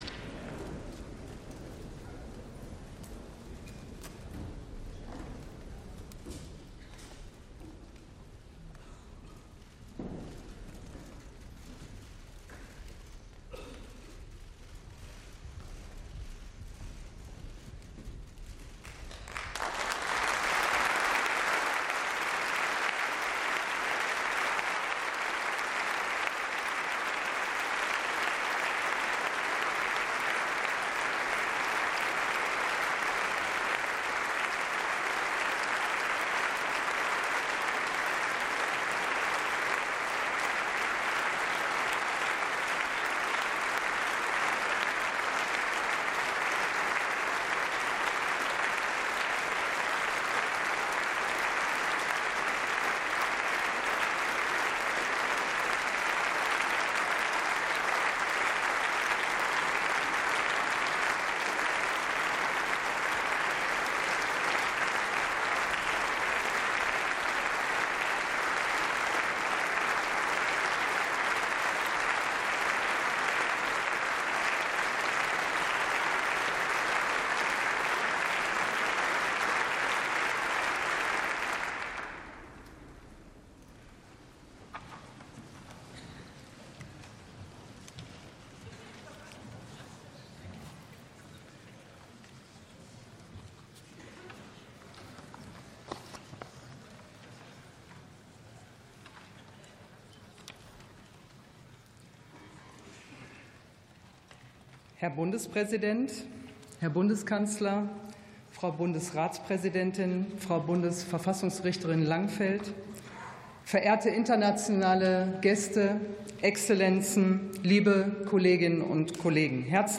Rede von Präsident Wolodymyr Selenskyj vor dem Bundestag ~ Sonderveranstaltungen - Audio Podcasts Podcast